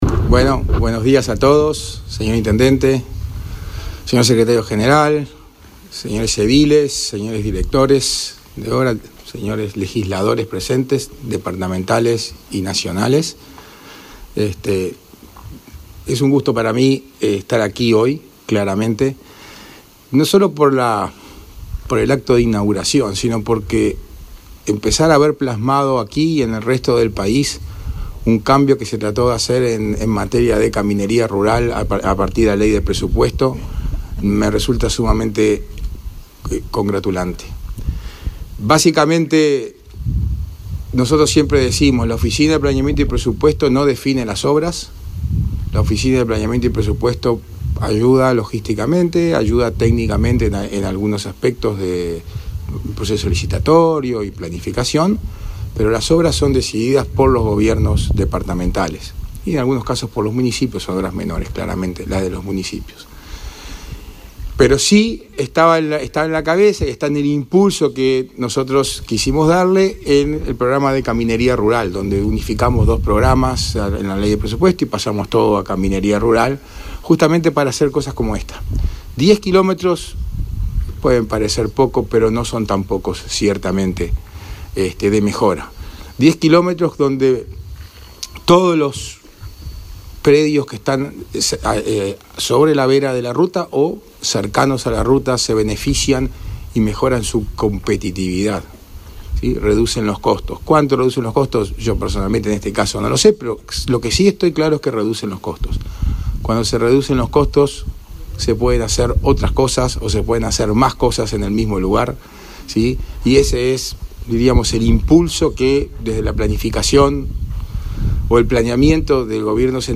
Conferencia de prensa por inauguración de obras de caminería en Colonia
Conferencia de prensa por inauguración de obras de caminería en Colonia 23/03/2022 Compartir Facebook X Copiar enlace WhatsApp LinkedIn Por una inversión de más de 30 millones de pesos, se inauguraron, este 23 de marzo, las obras de caminería en Colonia del Sacramento. Participaron de la actividad el director de la Oficina de Planeamiento y Presupuesto, Isaac Alfie, y el intendente de Colonia, Carlos Moreira.